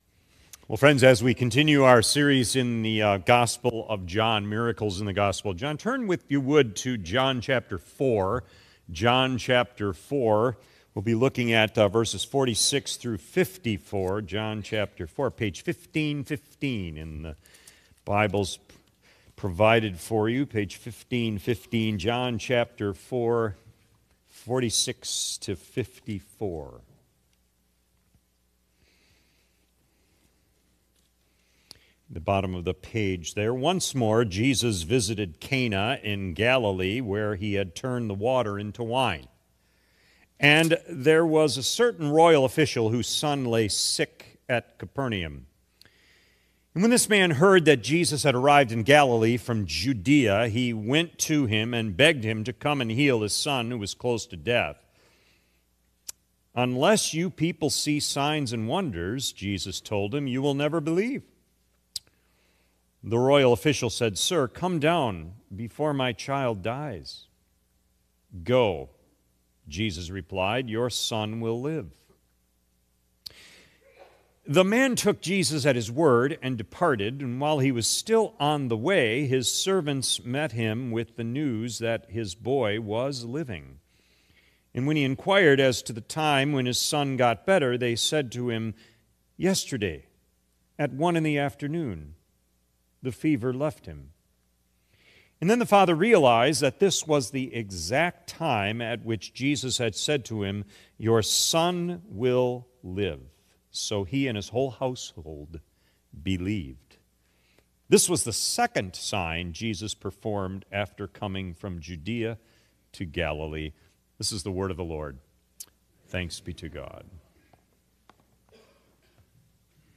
“Remote Work” January 18 2026 P.M. Service